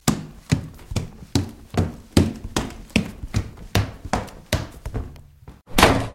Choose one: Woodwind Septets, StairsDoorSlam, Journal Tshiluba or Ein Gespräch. StairsDoorSlam